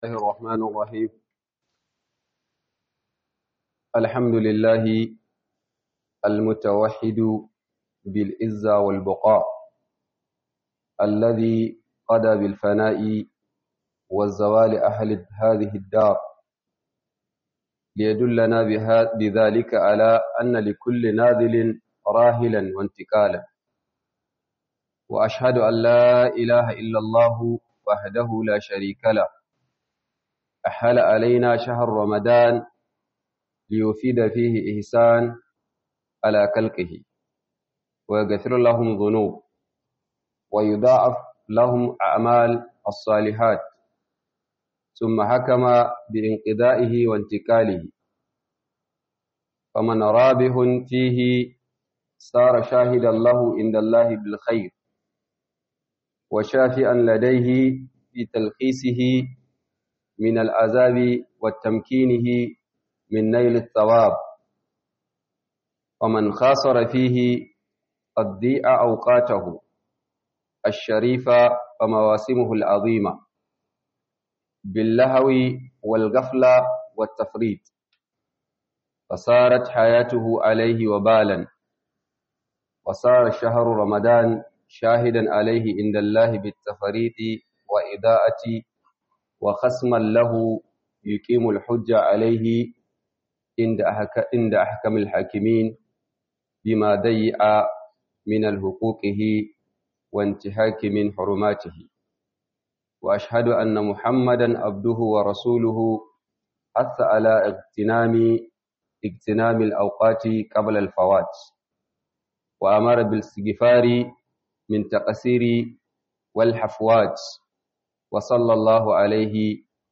Khudubar Low-cost Ningi - Ayyuka bayan Ramadan - Khudubar Sallar Juma'a by JIBWIS Ningi